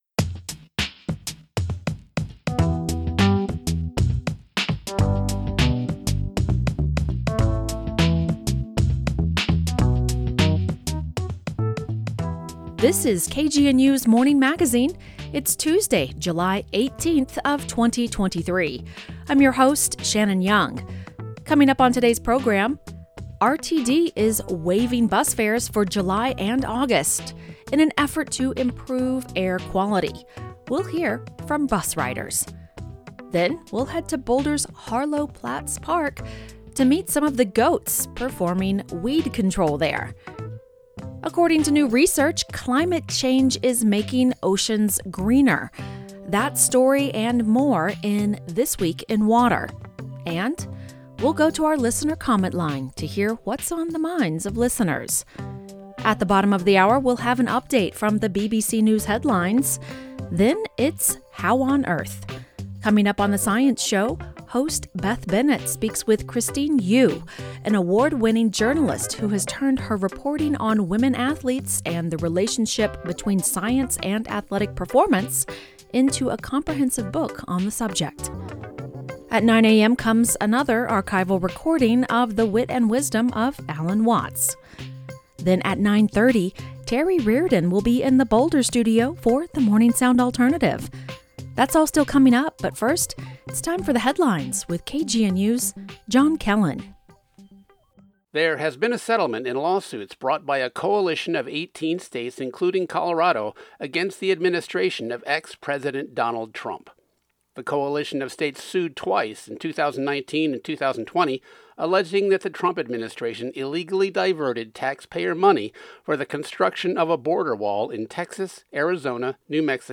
On today’s Morning Magazine, we’ll hear from bus riders as RTD waives fares for July and August in an effort to improve air quality. Then we’ll head to Boulder’s Harlow Platts Park to meet some of the goats performing weed control there. According to new research, climate change is making oceans greener.
Then, we’ll go to our listener comment line to hear what’s on the minds of listeners.